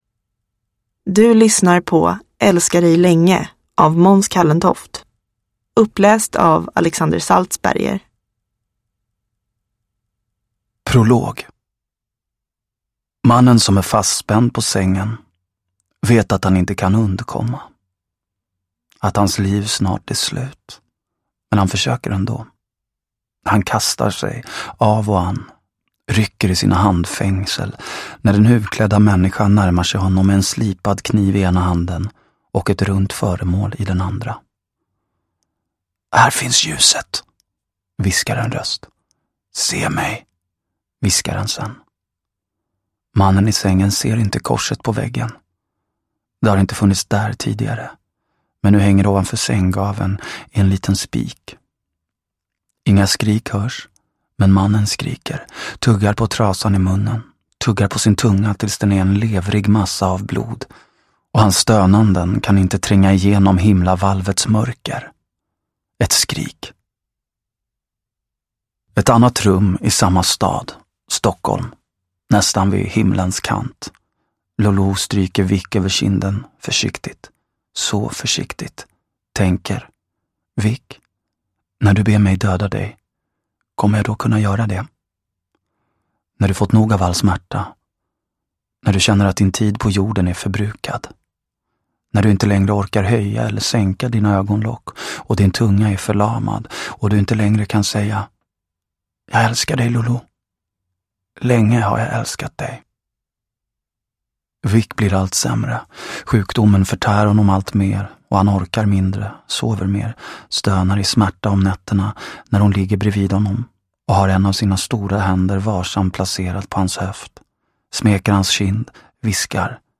Älska dig länge – Ljudbok – Laddas ner